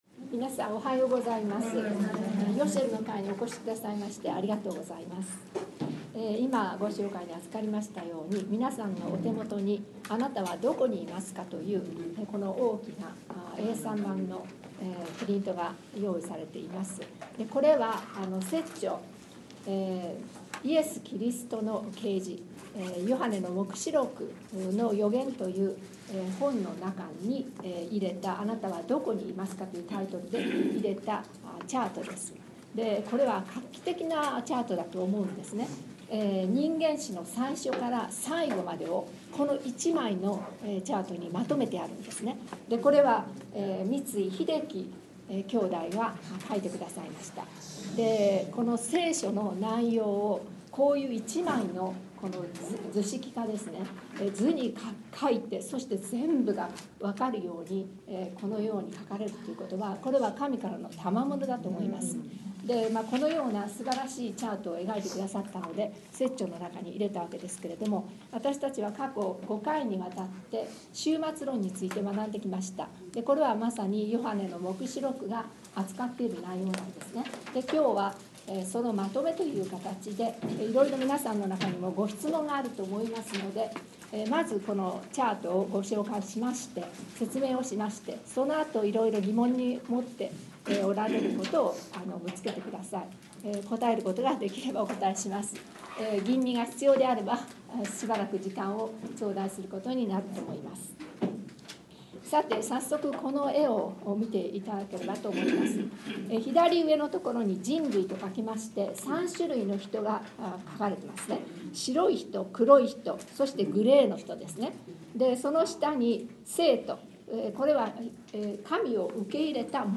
今回は、前回までの５回の終末論の学びを終えたことを機に、復習と質疑応答を中心とした学びの時間となりました。